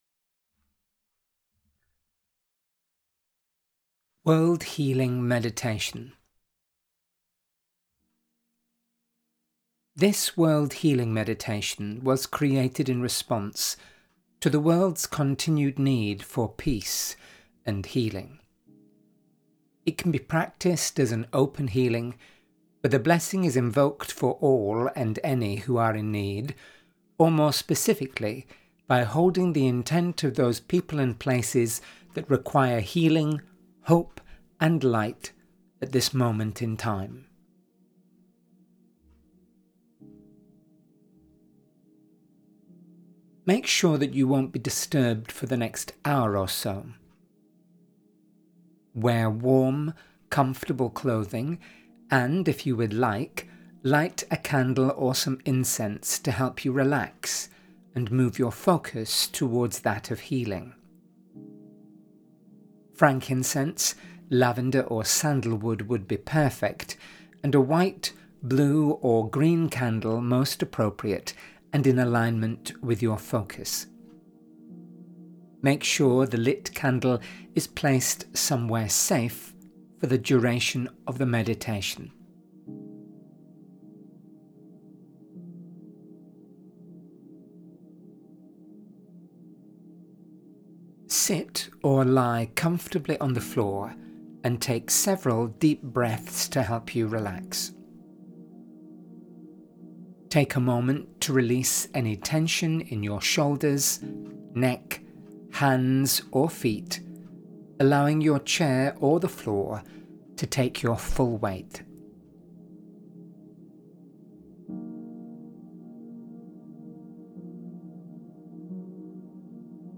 A unique healing meditation that draws upon not only the power of peace inside us all but also the elements as couriers and harbingers for this peace around the world.